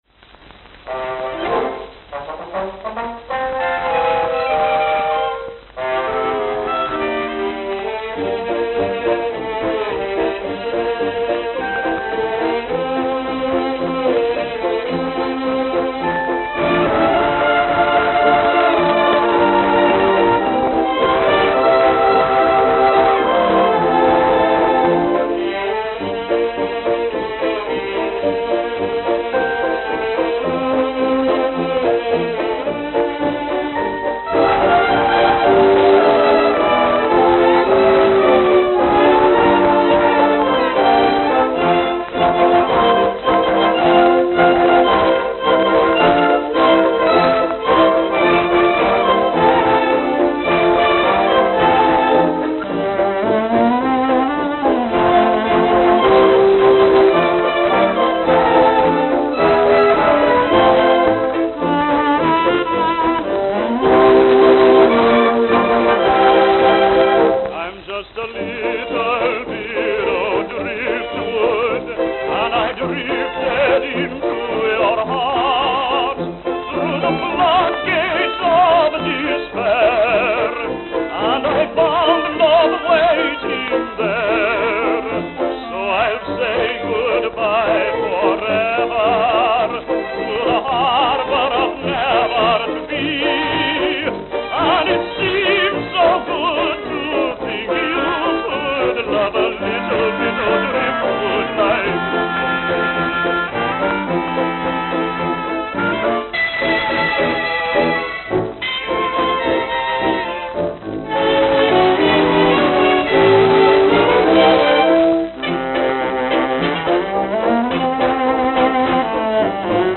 Note: Very worn.